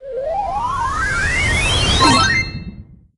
enemy_char_respawn_01.ogg